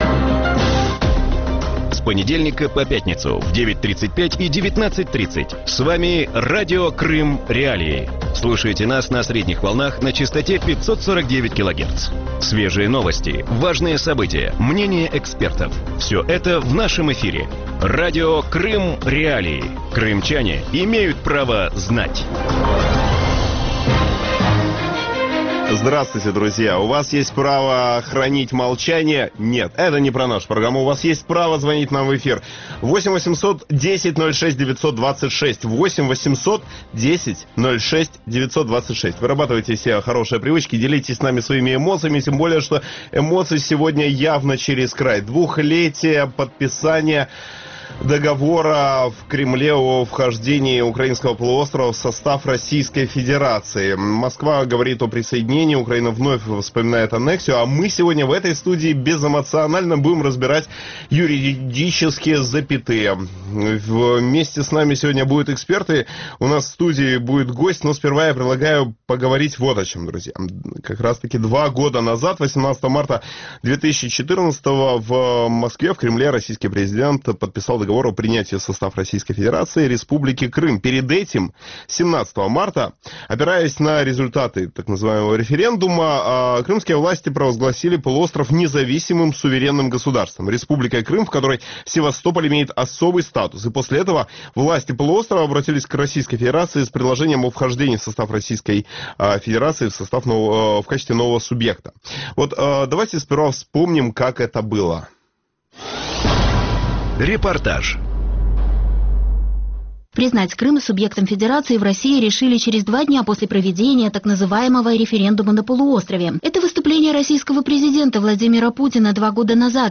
В вечернем эфире Радио Крым.Реалии обсуждают правовые аспекты аннексии Крыма. Верно ли утверждение российских властей, что Крым был присоединен с учетом всех международных норм?